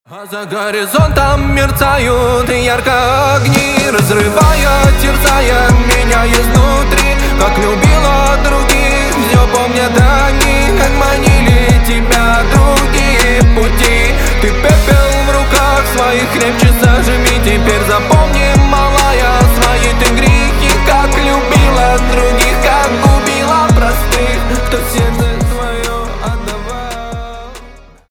на русском грустные